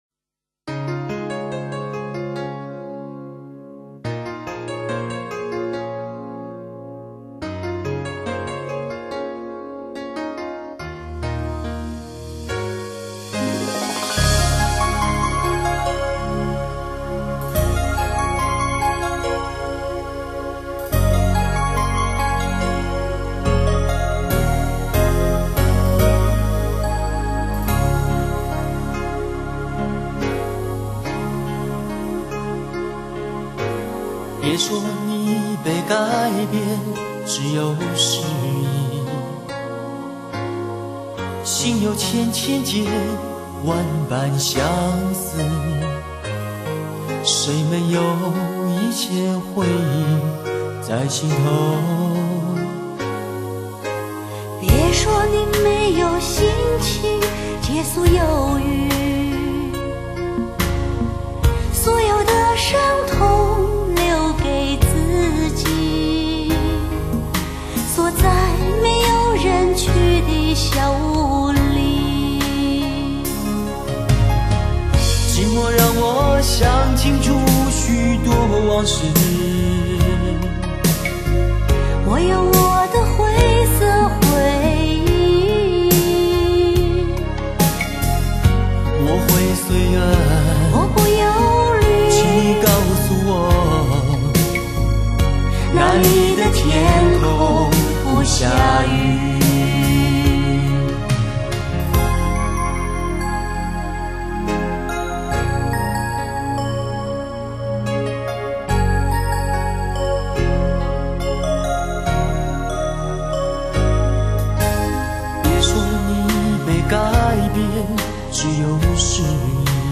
头版靓声，原音重现，选用100%头版母带直刻， 音色隽永细腻、新切、和谐，
炉火纯青的唱功，登峰造极的演绎，